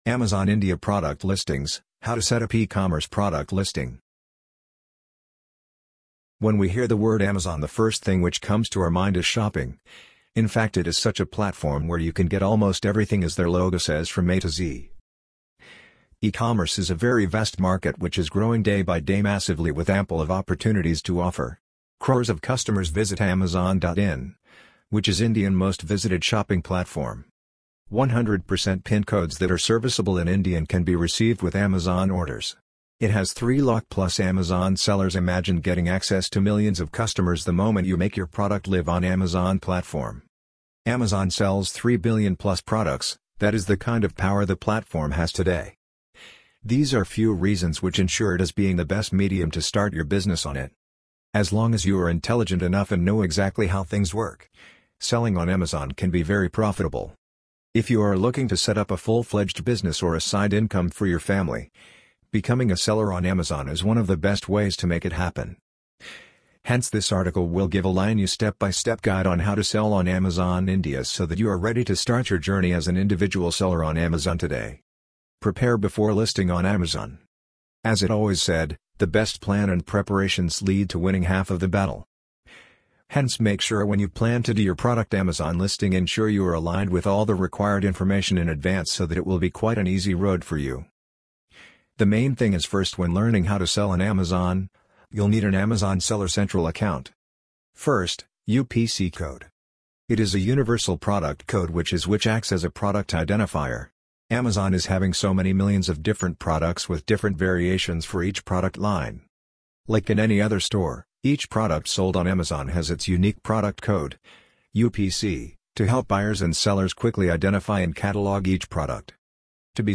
amazon_polly_5432.mp3